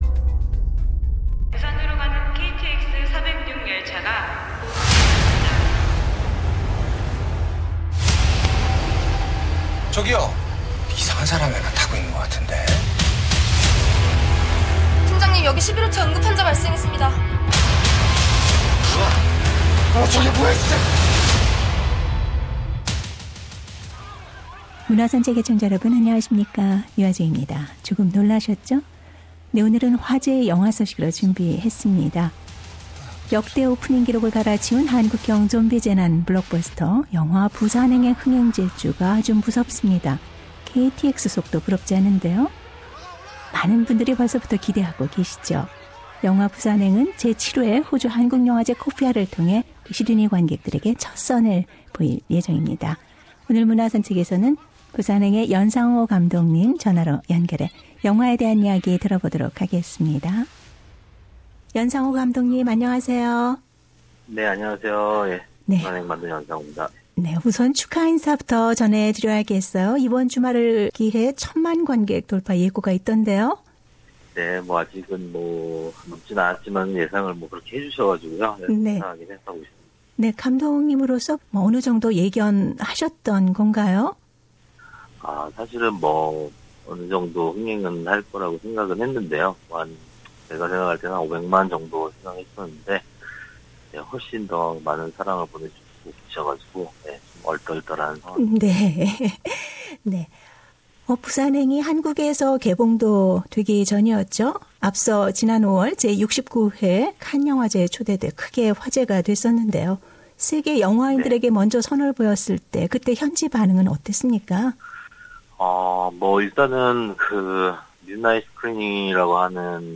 Director Yeon speaks to SBS Radio Korean Program Share